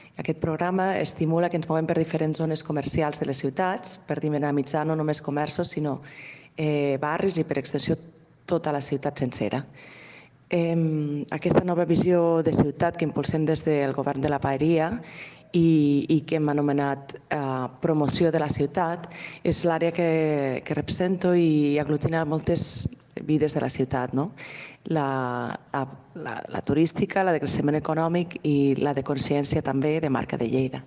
TALL DE VEU